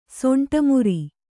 ♪ soṇṭa muri